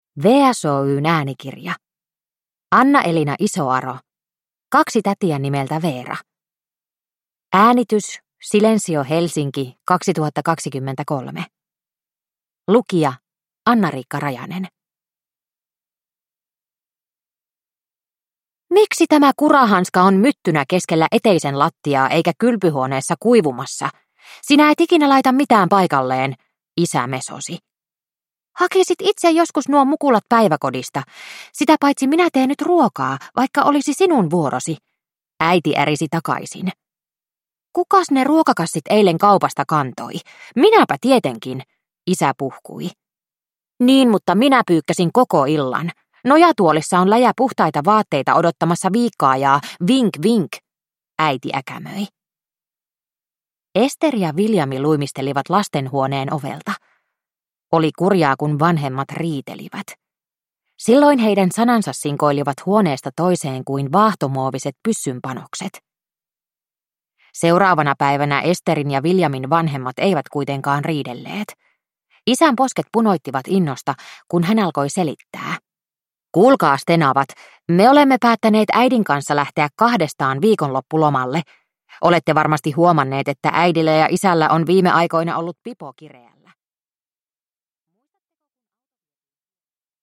Kaksi tätiä nimeltä Veera – Ljudbok – Laddas ner